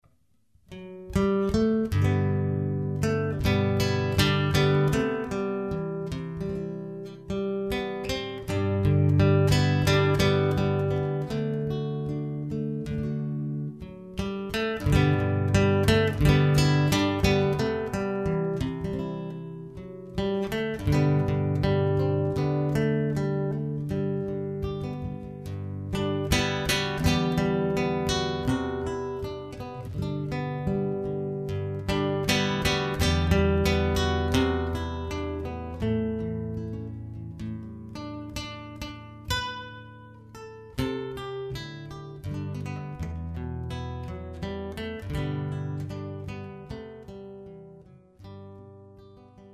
Gitarrist
Danny Boy (Irish Folk)